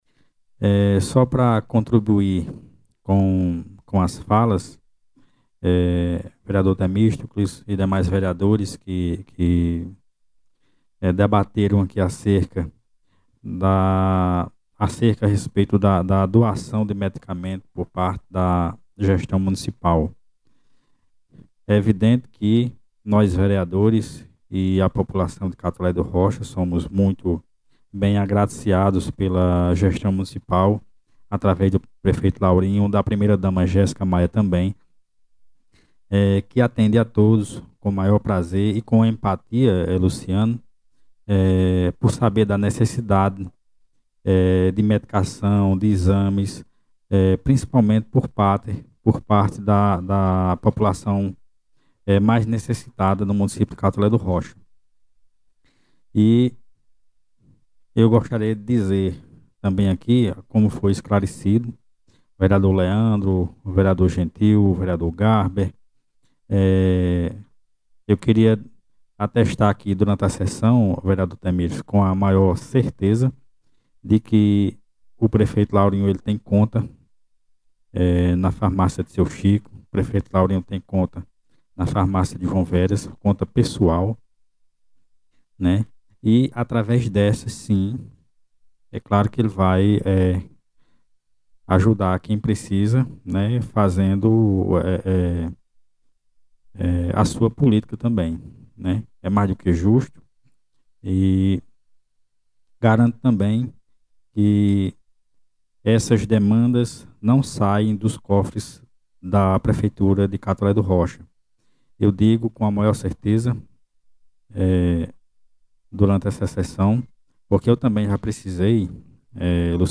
Durante a sessão ordinária da Câmara Municipal de Catolé do Rocha, o vereador Daniel Cavalcante saiu em defesa do prefeito Lauro Adolfo Maia Serafim (Laurinho Maia), diante de discussões sobre o fornecimento de medicamentos à população local.
Ouça o trecho da fala do pronunciamento do Verador Daniel Cavalcante: